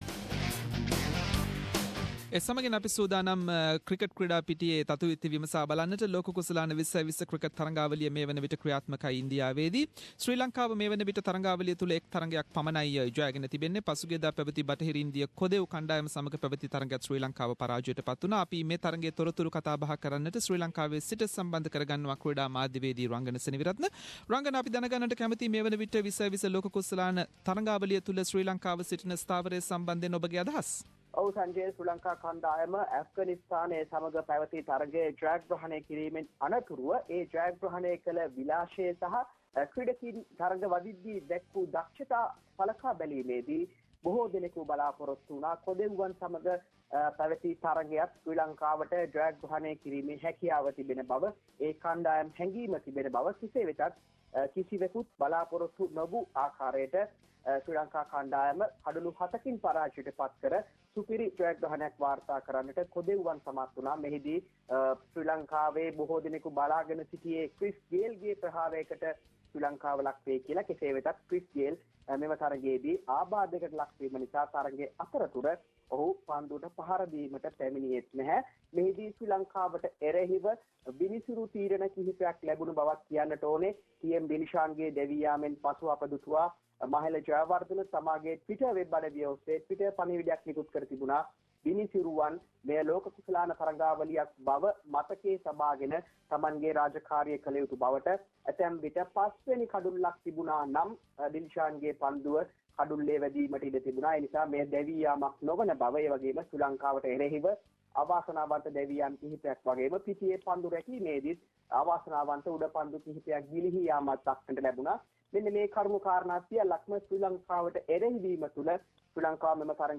T20 Cricket world cup latest in this weeks sports segment. Sports journalist